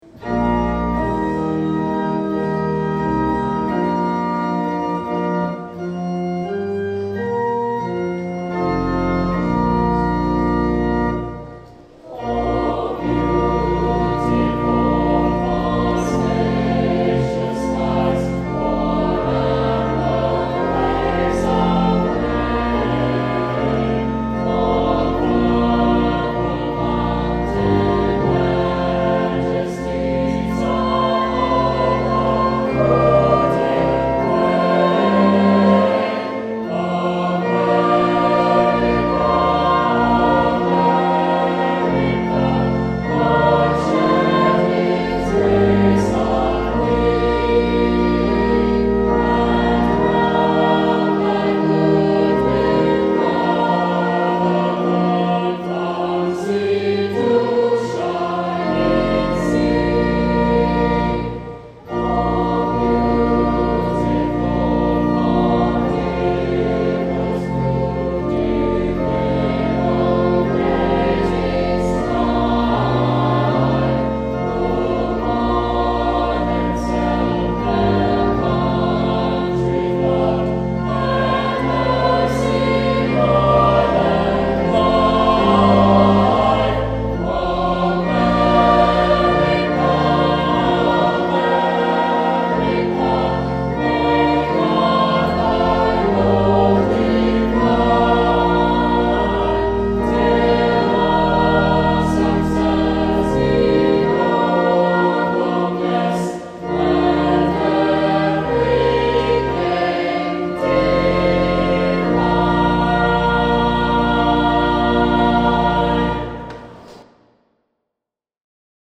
Text by Katherine I. Bates; Music by Samuel A. Ward
Saint Clement Choir Sang this Song